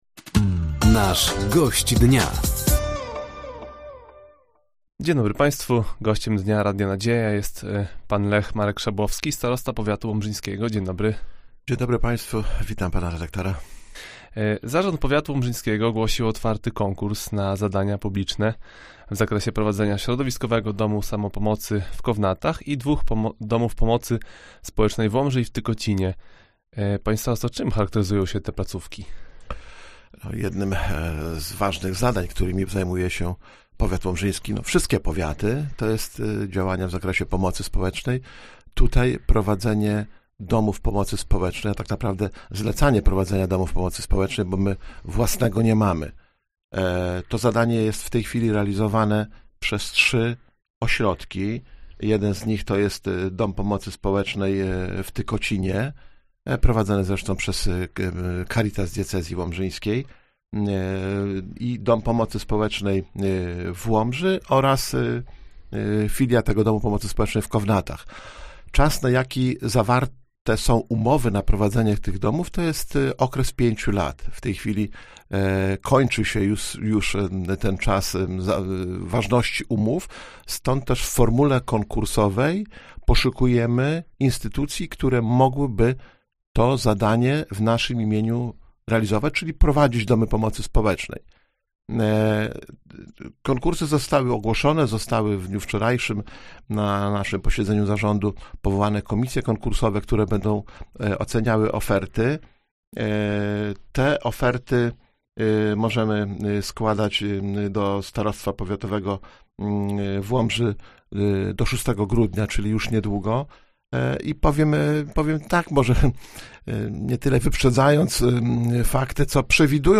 Gościem Dnia Radia Nadzieja był starosta powiatu łomżyńskiego, Lech Marek Szabłowski. Tematem rozmowy było między innymi podsumowanie realizacji inwestycji wraz z przywołaniem ogólnopolskich statystyk, konkurs dotyczący Domów Pomocy oraz działalność Regionalnego Ośrodka Kultury w Łomży.